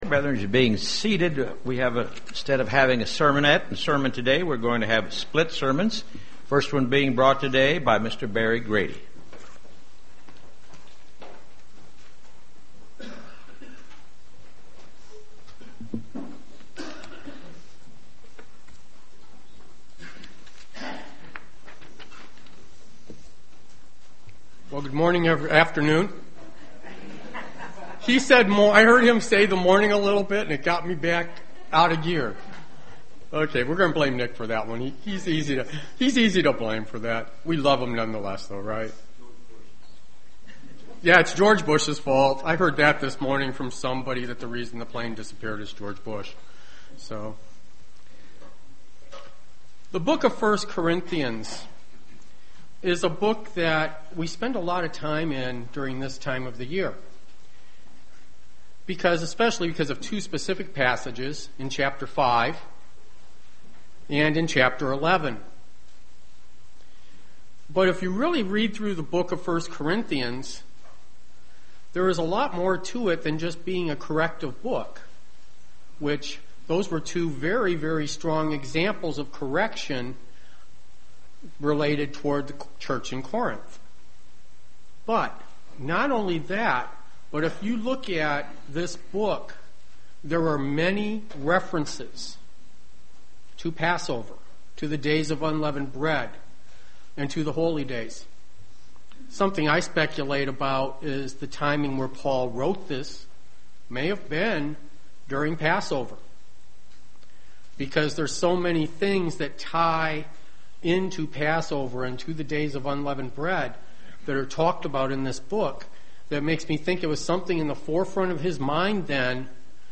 Sermon applicable to the Passover season